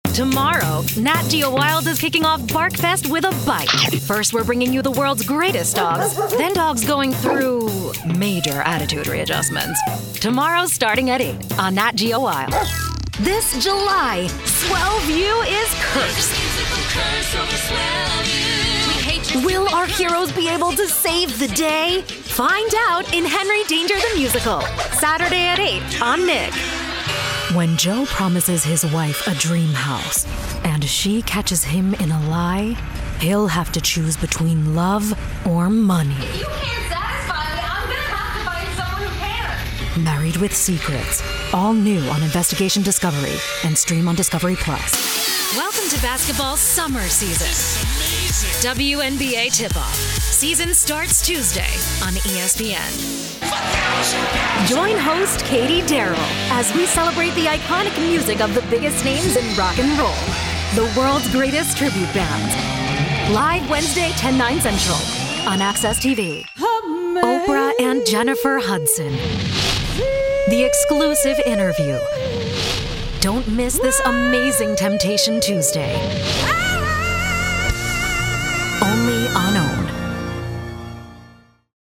new york : voiceover : animation